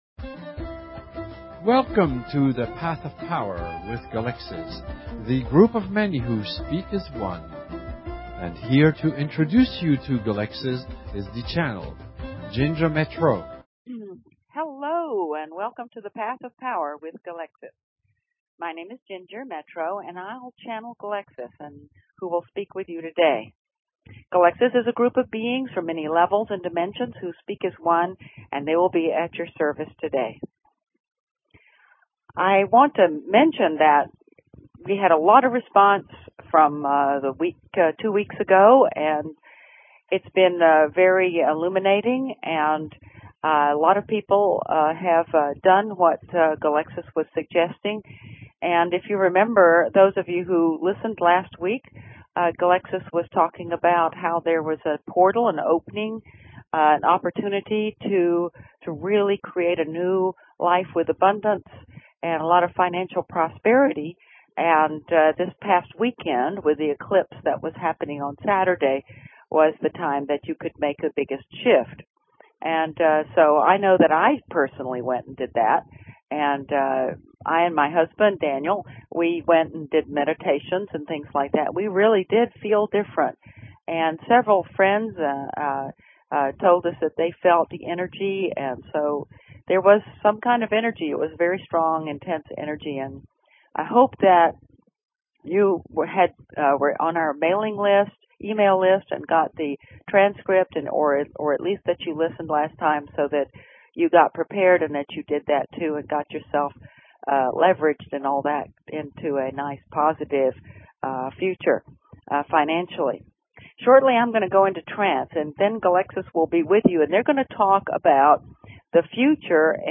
Talk Show Episode, Audio Podcast, Path_of_Power and Courtesy of BBS Radio on , show guests , about , categorized as